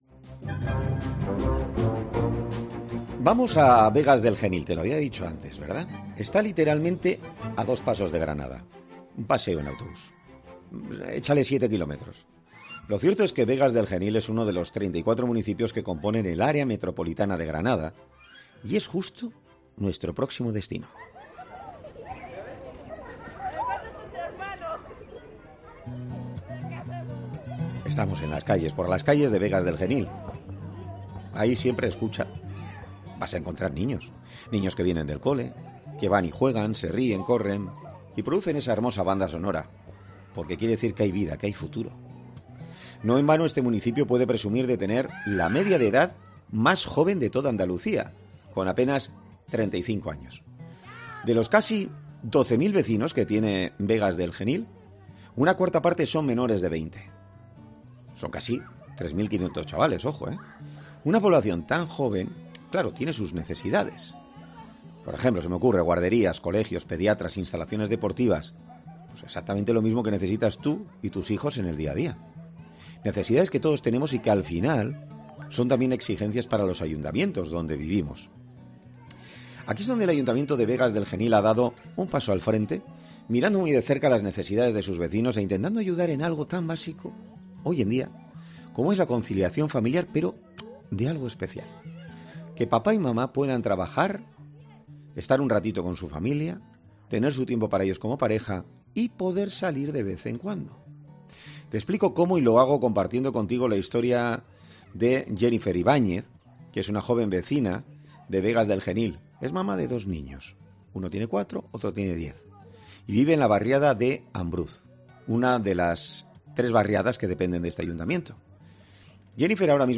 'Herrera en COPE' habla con una vecina y el alcalde de este municipio, quien ha sido el artífice de esta iniciativa tan bien recibida por sus vecinos
En 'Herrera en COPE' hablamos con Leandro Martín, alcalde de Vegas del Genil sobre cómo surgió esta idea